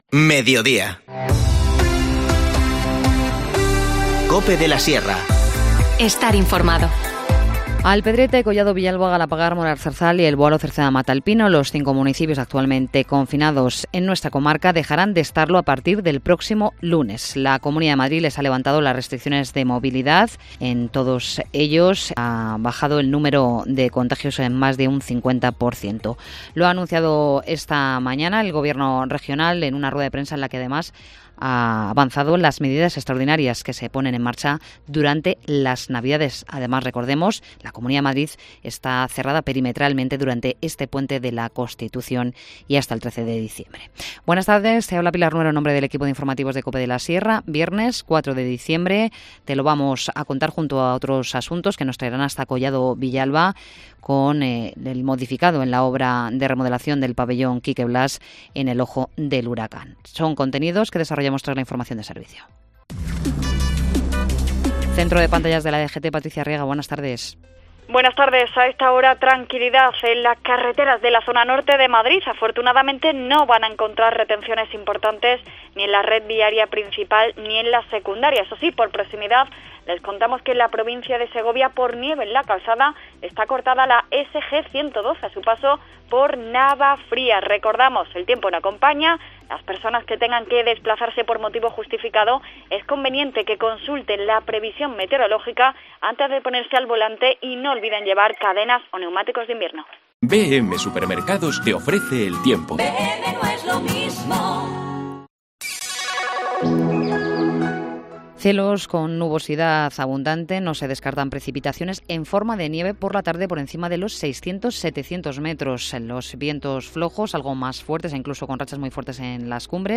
Informativo Mediodía 4 diciembre